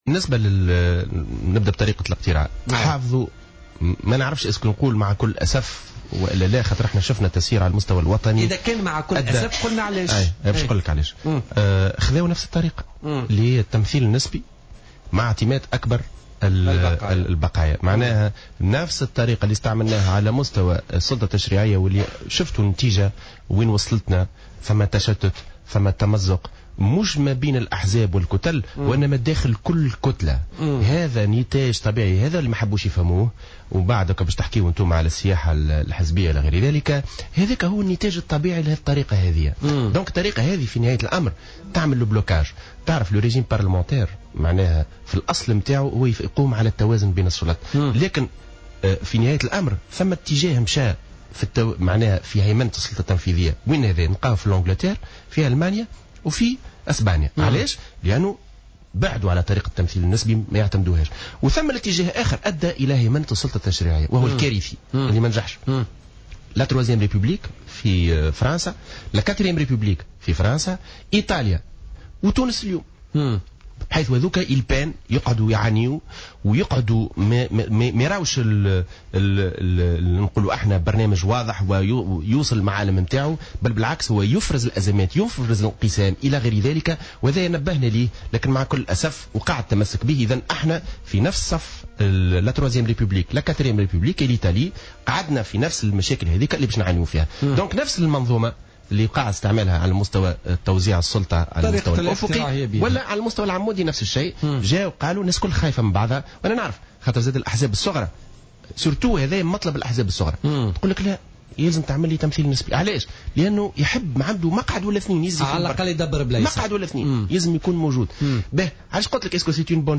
وقال خلال مداخلة له اليوم في برنامج "بوليتيكا" إن هذه الطريقة أدت إلى التشتت ليس فقط بين الأحزاب والكتل وإنما أيضا داخل كل كتلة.